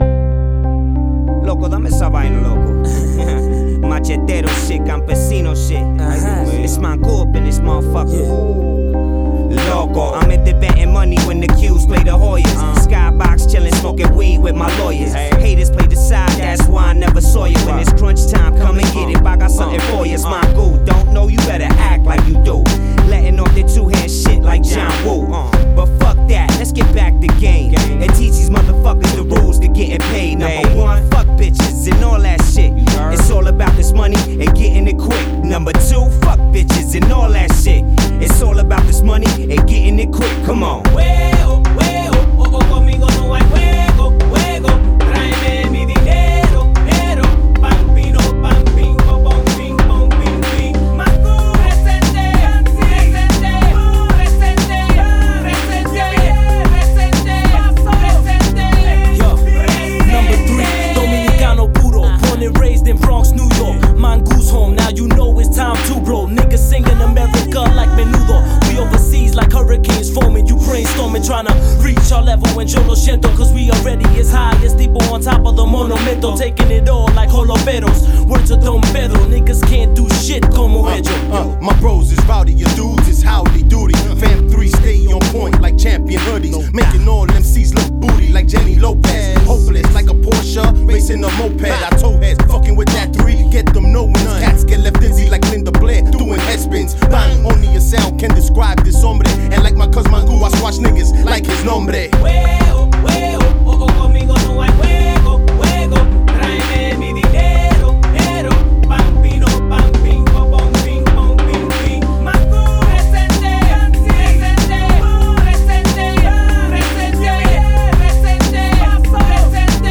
Rap
featuring all Dominican rappers from the Bronx, New York